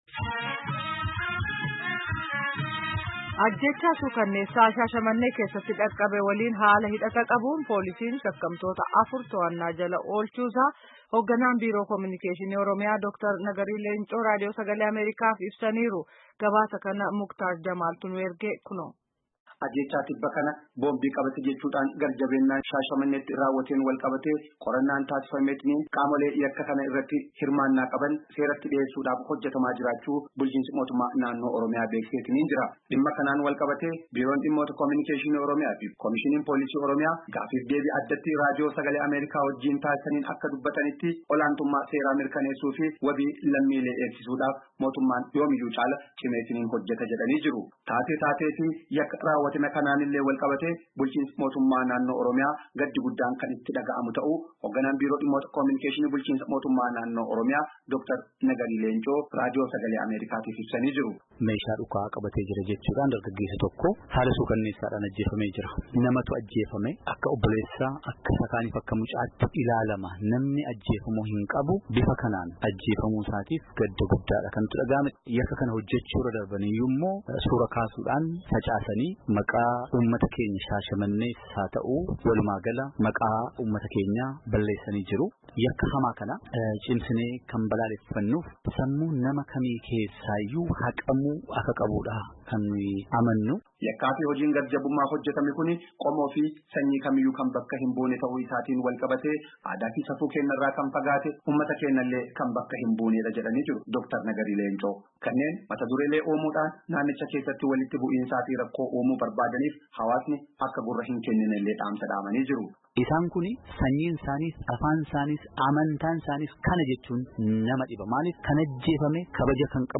Yakkaa ajeechaa sukaneessaa shaashamanee keessatti dhaqqabe waliin haala hidhata qabuun polisiin shakamtoota afur tohannaa jala oolchee qorata akka jiru hogganaan Biroo Komunikeeshinii Oromomiyaa Dr. Nagarii Leencoo Raadiyoo Sagalee Ameerikaaf ibsanii jiran.